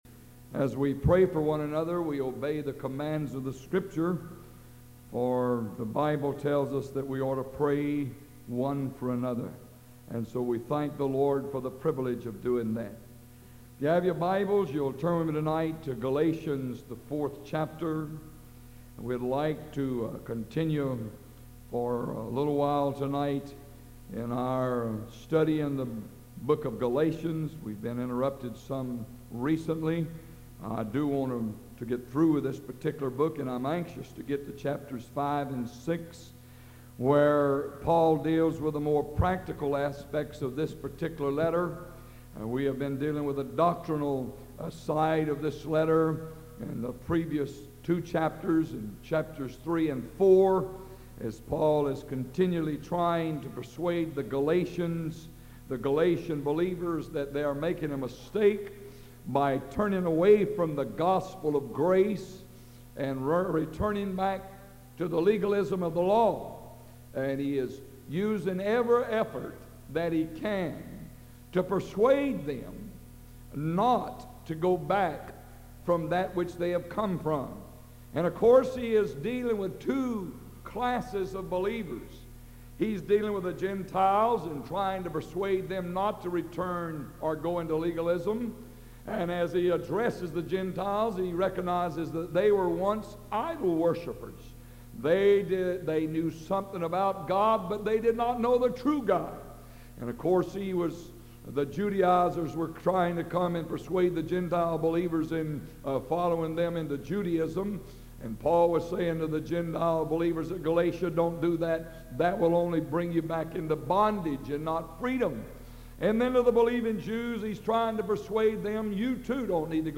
Galatians Study – November 6, 1985